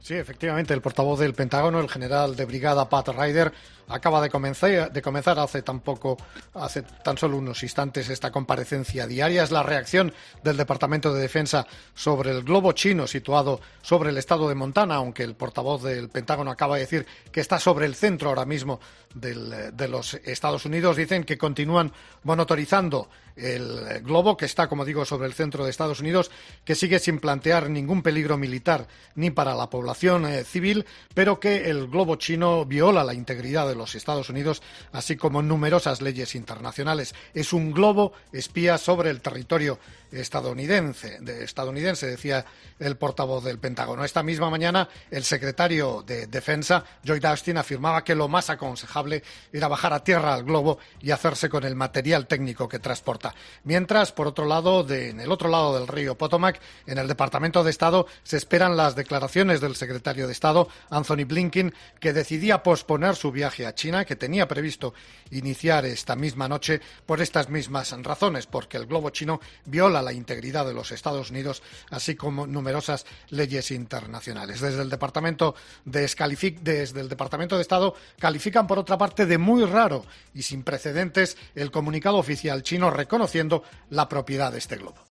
Te da más detalles desde Washington